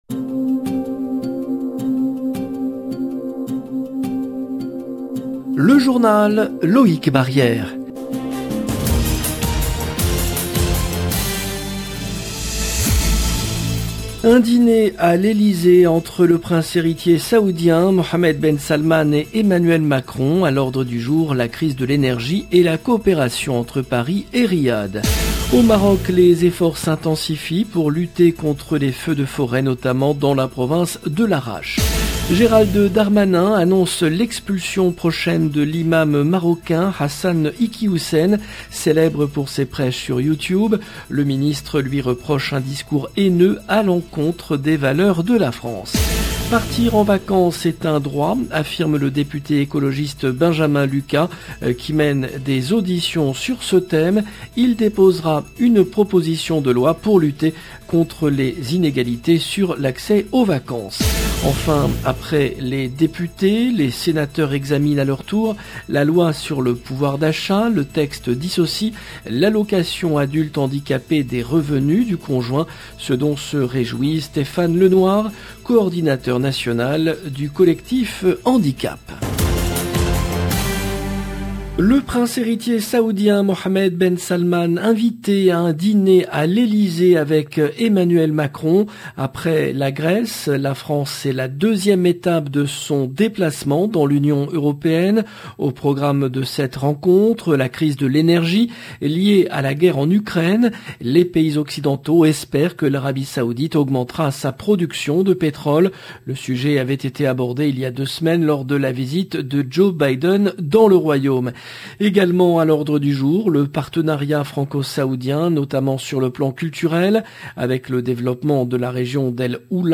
LE JOURNAL DU SOIR EN LANGUE FRANCAISE DU 28/07/22